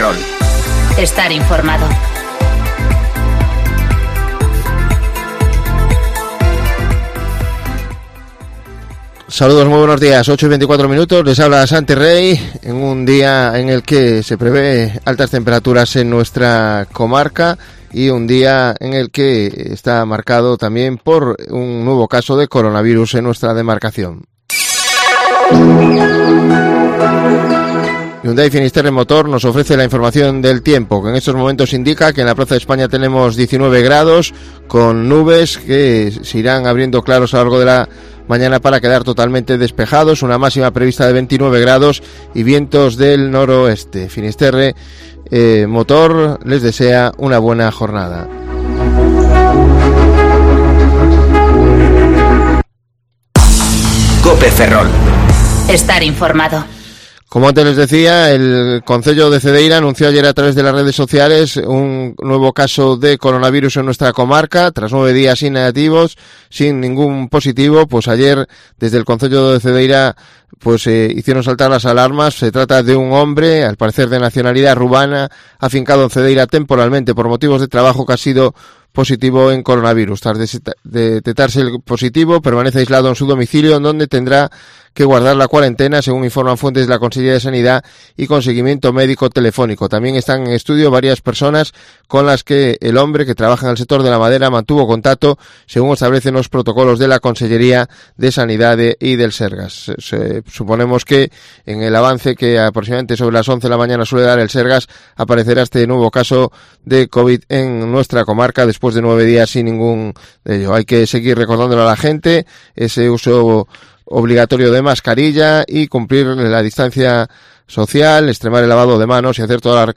Informativo Cope Ferrol 29/07/2020 (De 8:20 a 8:30 horas)